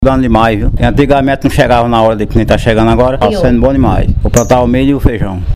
Fala do agricultor